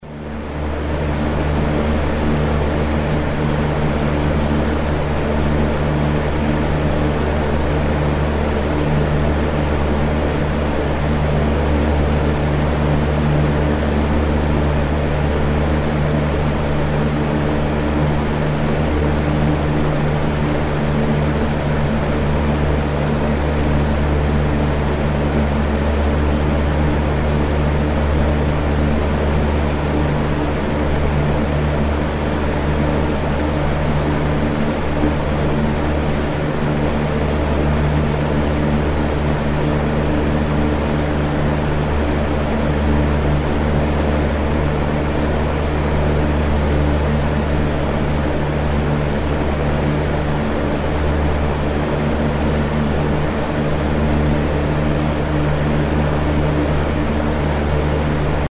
Звук віконного вентилятора